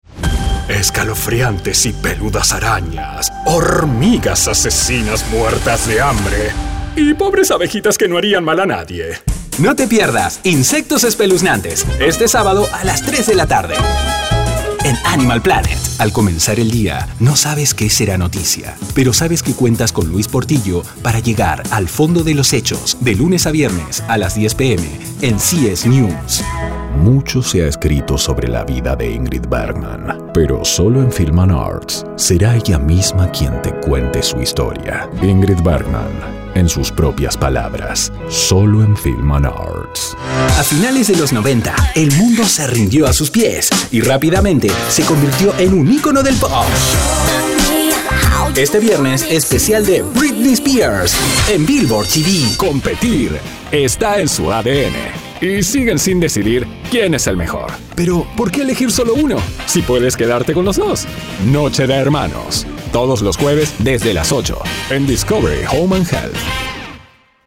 Commercial | Neutral Spanish
promos_tv.mp3